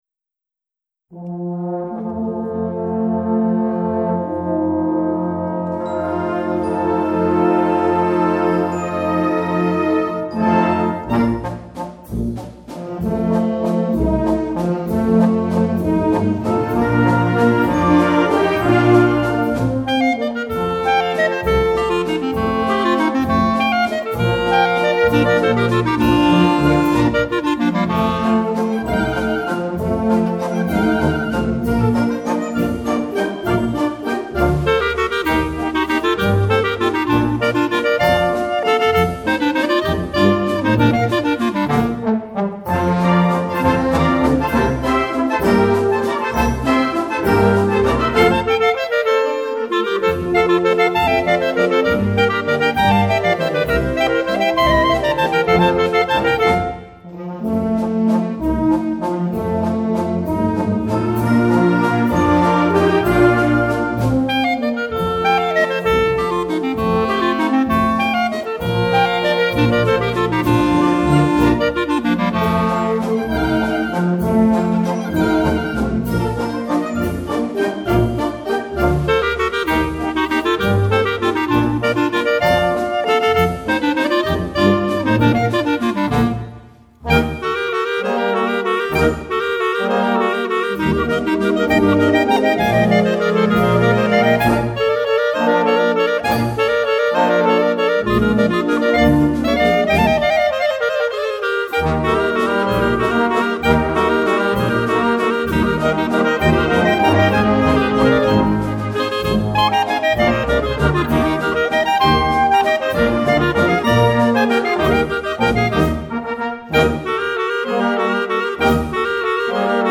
• Blasmusik / Brassband